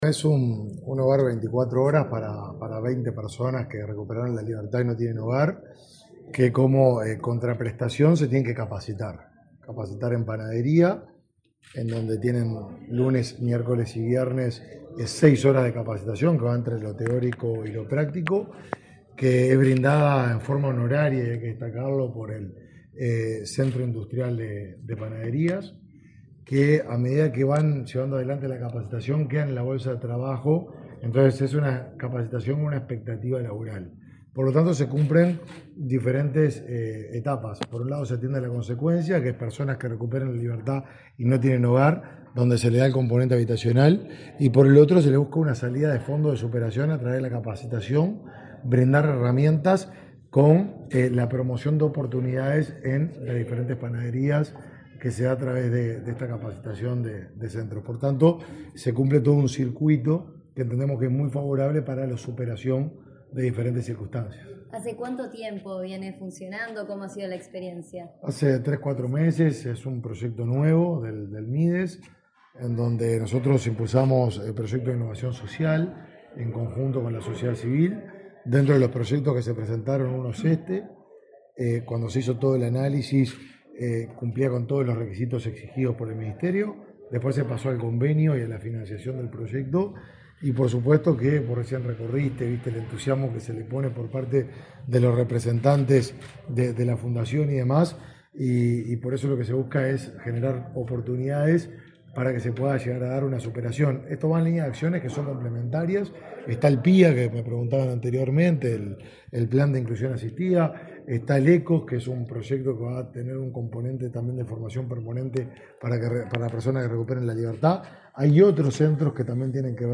Declaraciones el ministro de Desarrollo Social, Martín Lema
El ministro de Desarrollo Social, Martín Lema, dialogó con la prensa, luego de visitar el proyecto Hogar Cardoner, en Montevideo, que tiene como